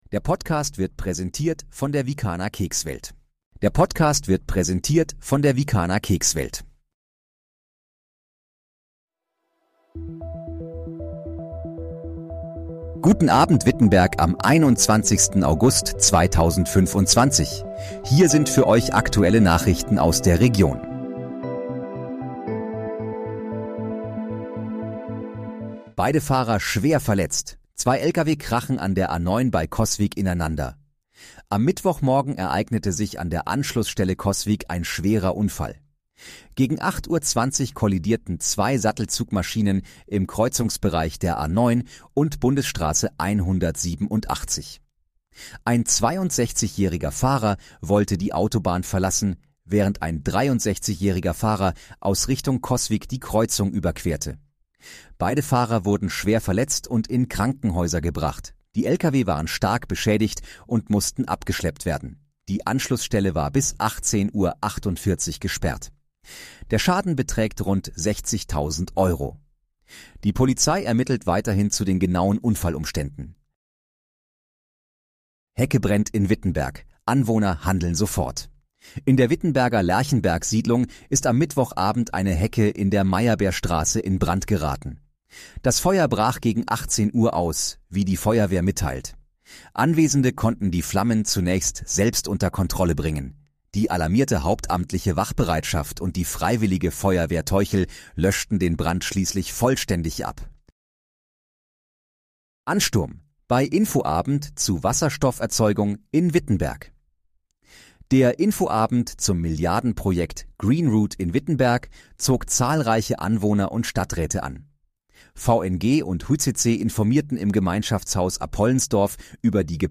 Guten Abend, Wittenberg: Aktuelle Nachrichten vom 21.08.2025, erstellt mit KI-Unterstützung